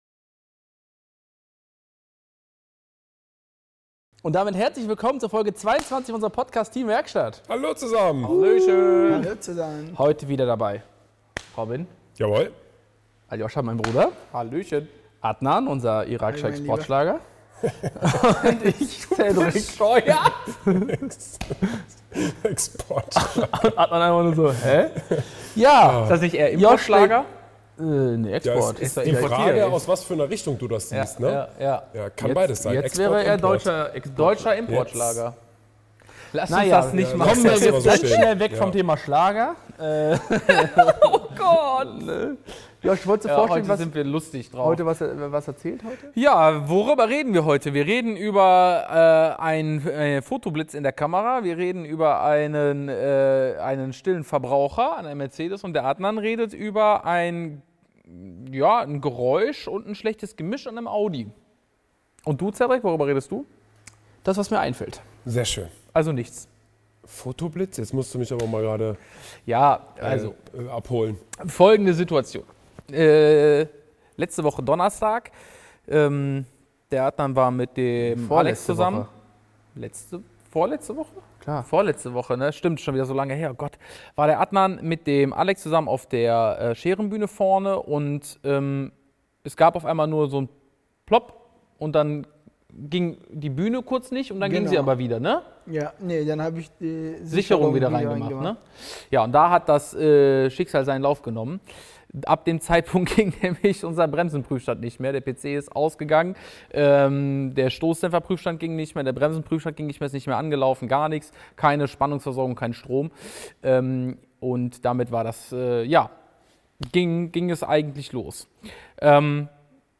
#22 LIVE-DIAGNOSE ~ TEAM WERKSTATT | Der Feierabend-Talk aus der Werkstatt der Autodoktoren Podcast